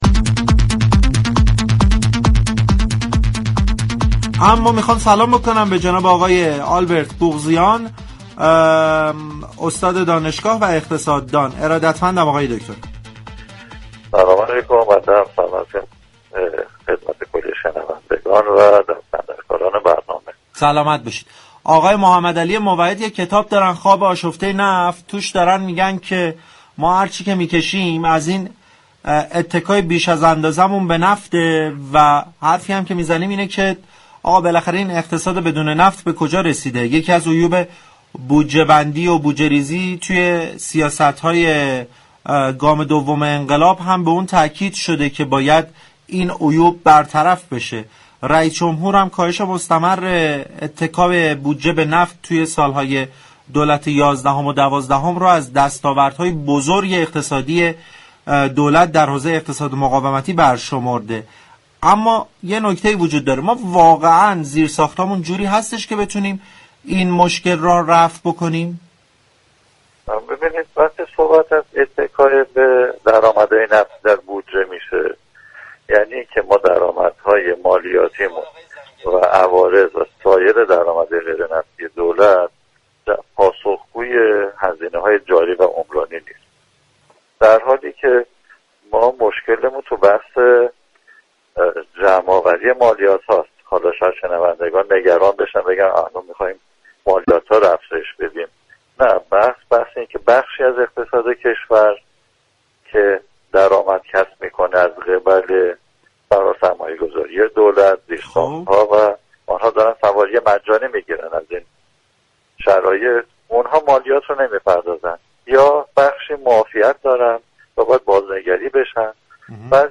در گفتگو با برنامه بازار تهران
مهمان غیرحضوری